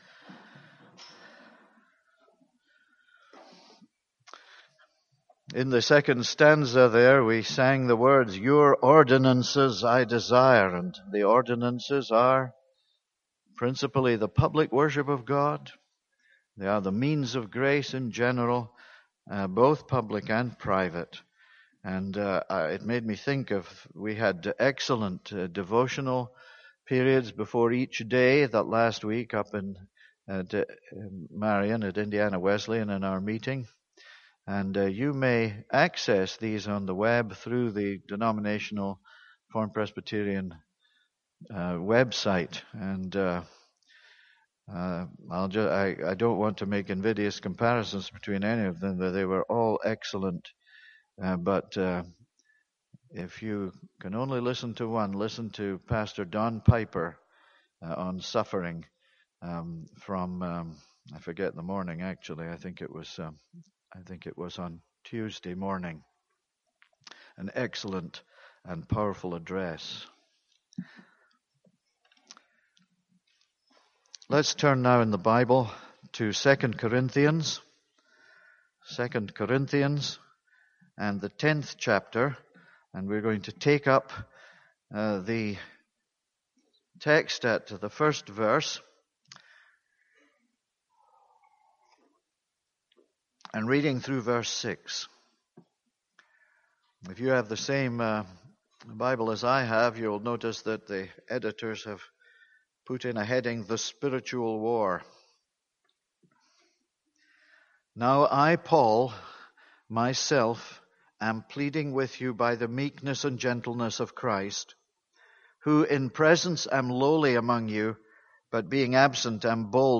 This is a sermon on 2 Corinthians 10:1-6.